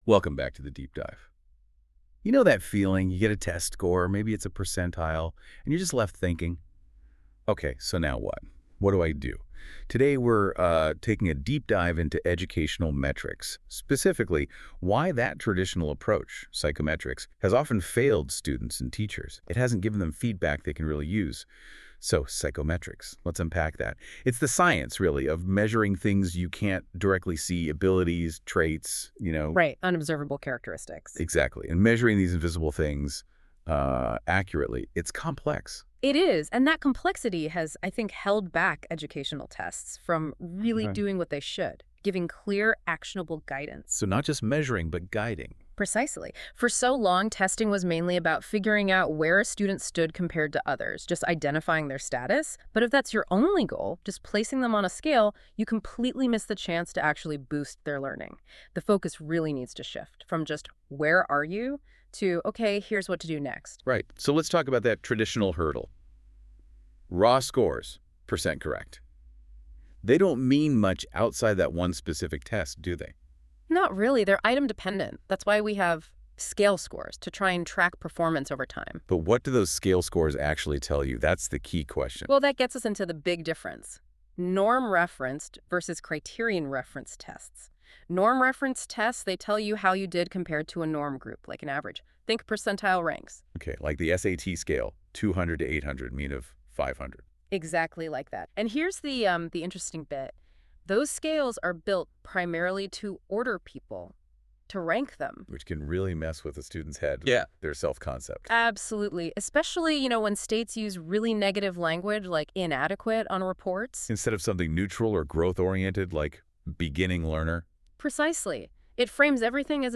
AI Audio Overview]